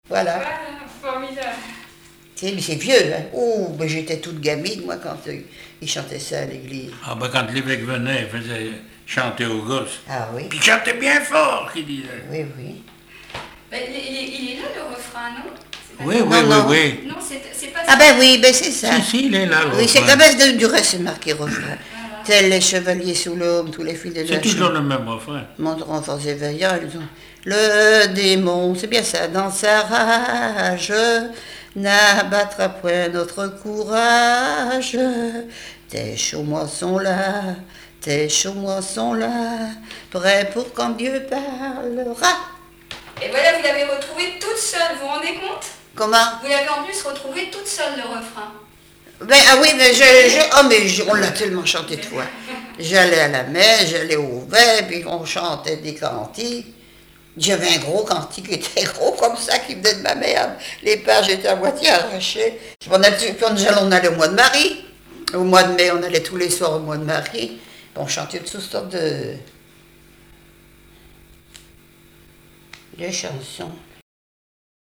Chansons et témoignages maritimes
Catégorie Témoignage